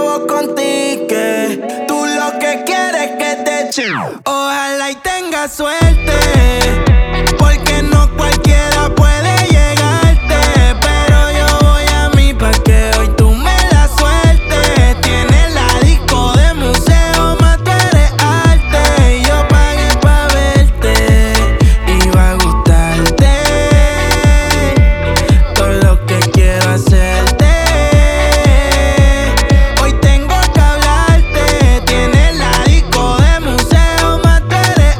Жанр: Латиноамериканская музыка / Русские